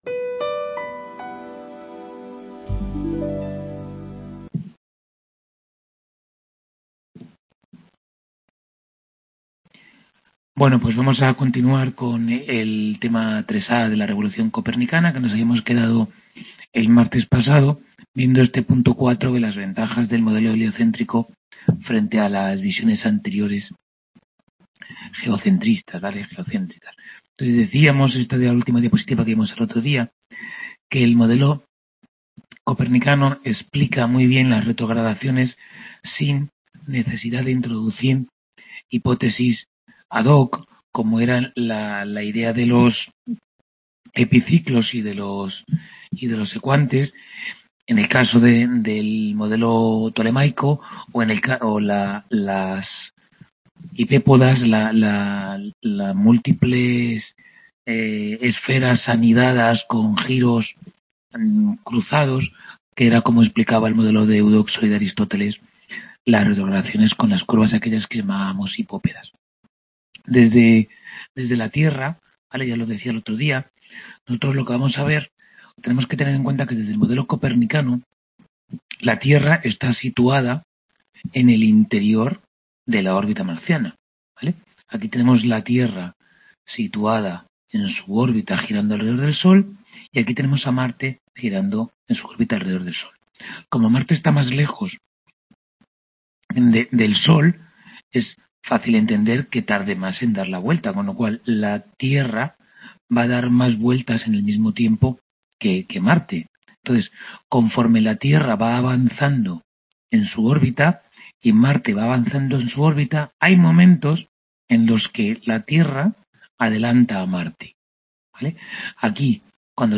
Tutoría 7a de Historia General de la Ciencia I | Repositorio Digital